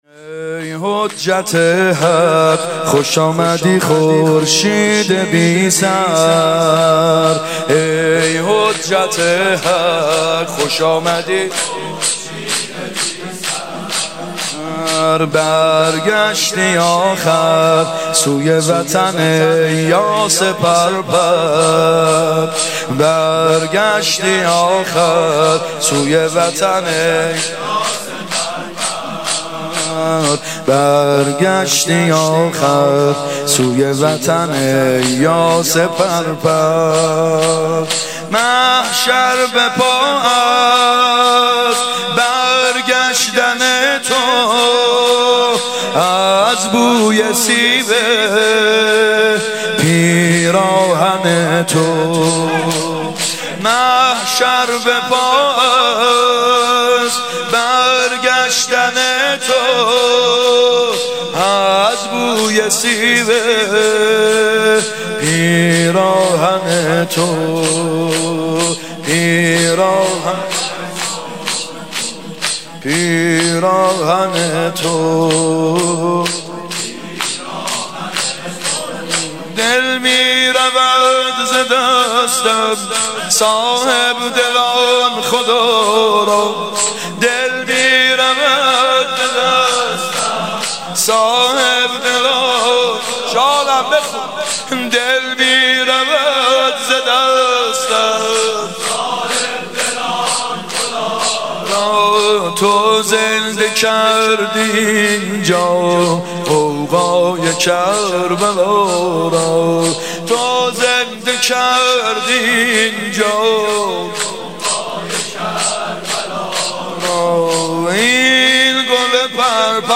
محرم 96 شب ششم شور شهید حججی (ای حجت حق